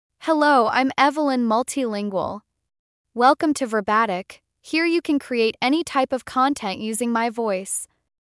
Evelyn MultilingualFemale English AI voice
Evelyn Multilingual is a female AI voice for English (United States).
Voice sample
Listen to Evelyn Multilingual's female English voice.
Evelyn Multilingual delivers clear pronunciation with authentic United States English intonation, making your content sound professionally produced.